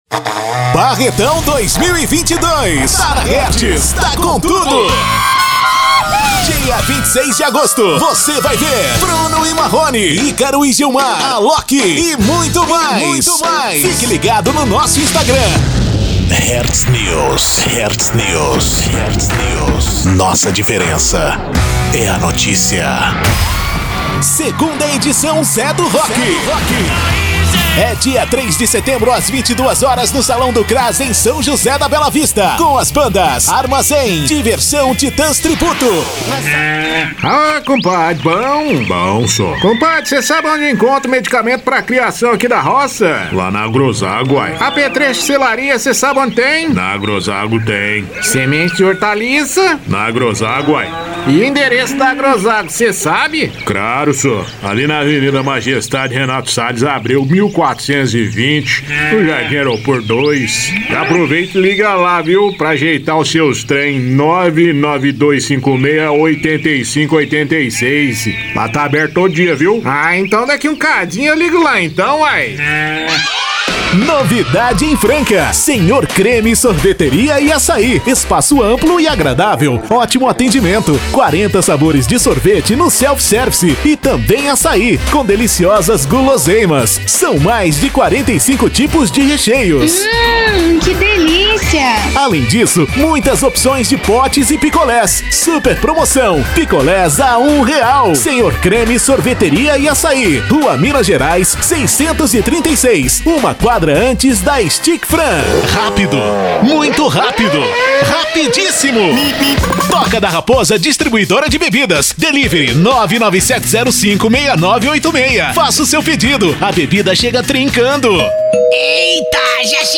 VOZES MASCULINAS
Estilos: Padrão